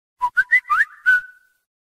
soft-hitclap.wav